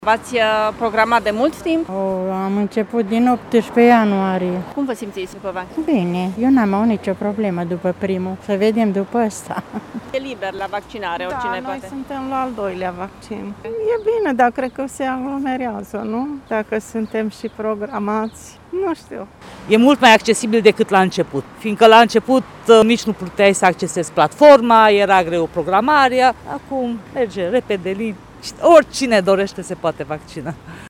Târgumureșenii care s-au prezentat astăzi la vaccinare se tem că s-ar putea crea aglomerări dacă vor veni multe persoane pe lângă cele deja programate: